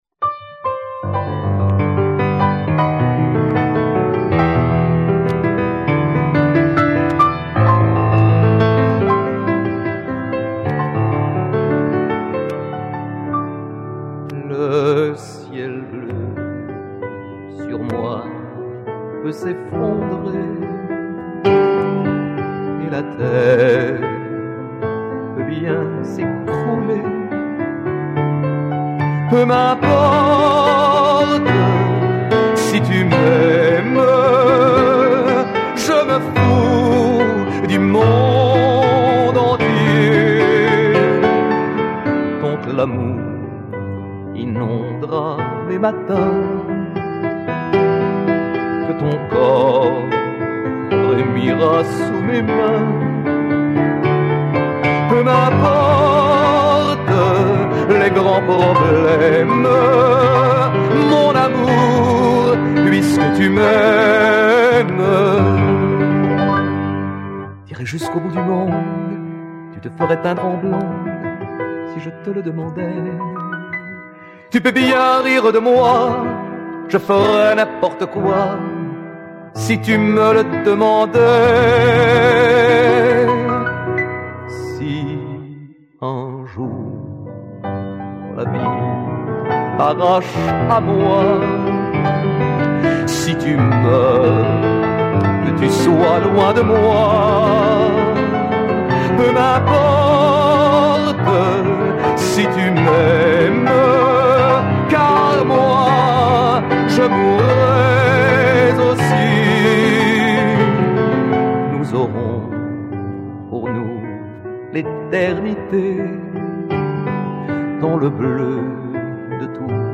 " and featured cabaret type styling of songs